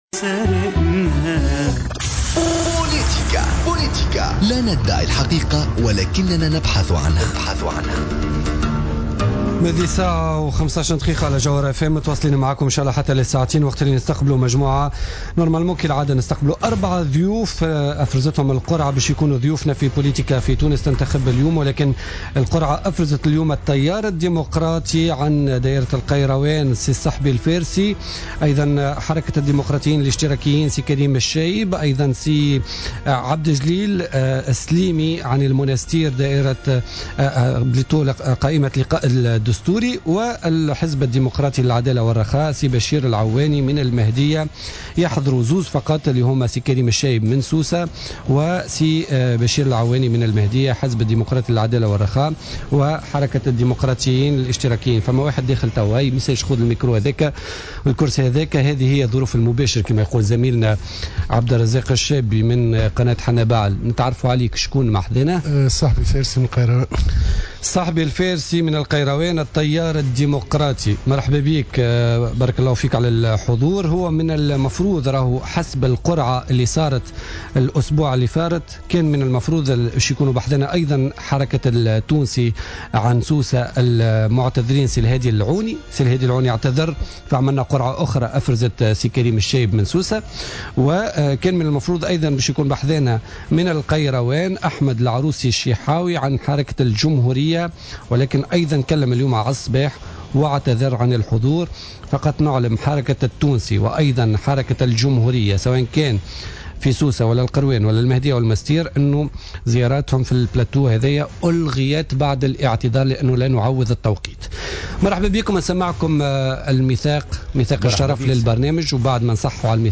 مداخلات ضيوف بوليتيكا